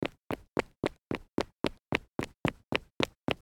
Звуки бегущего человека